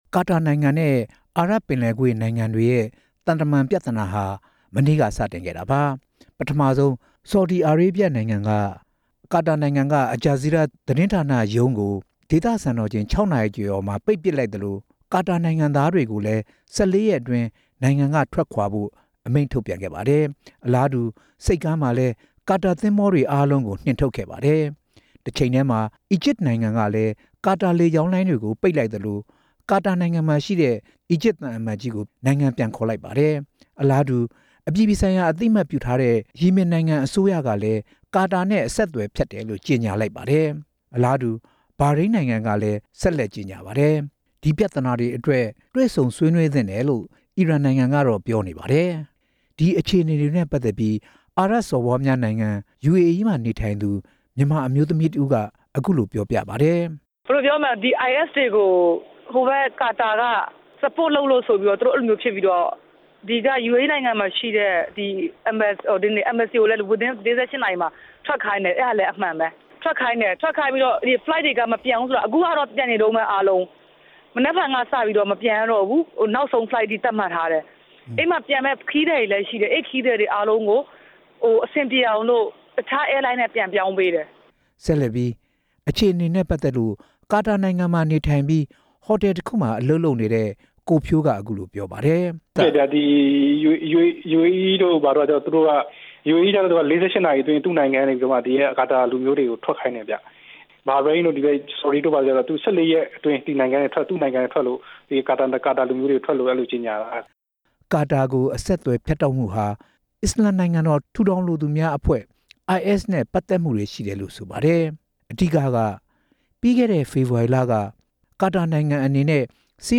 ဆက်သွယ်မေးမြန်းတင်ပြ ထားပါတယ်။